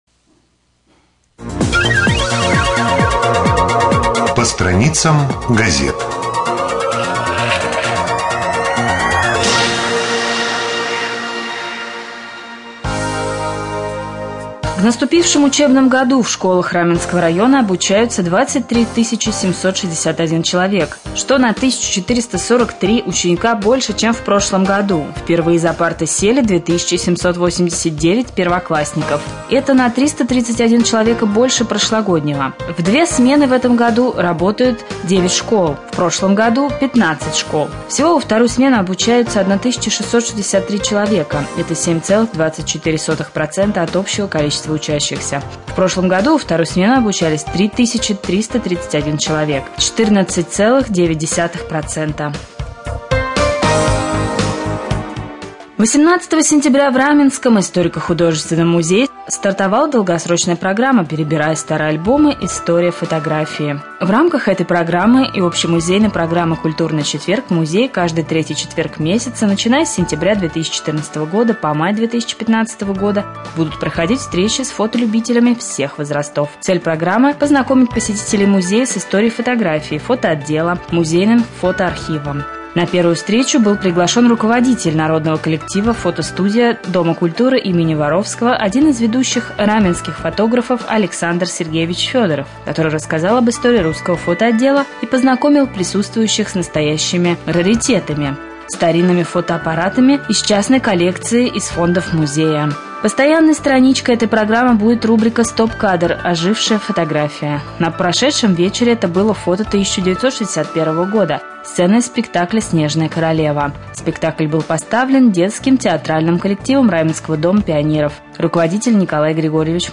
1.Рубрика «По страницам прессы». Новости